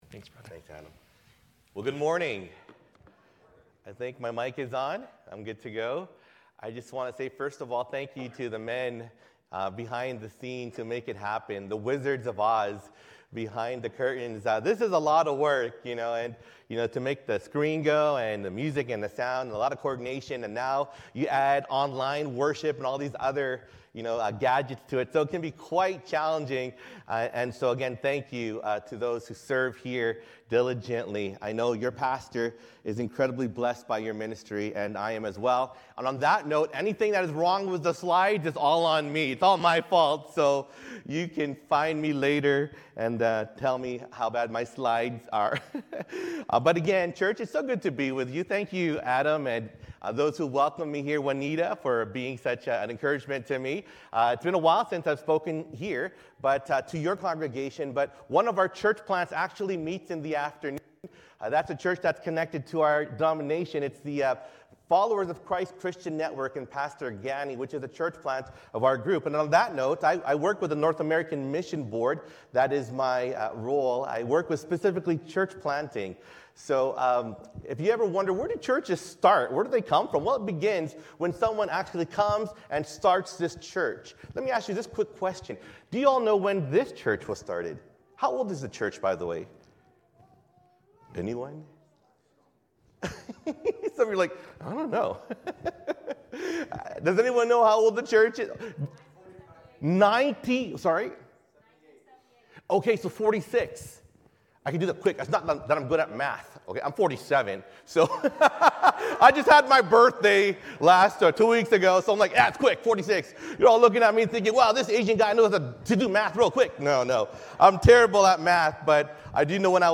Sermons | Sunrise Community Church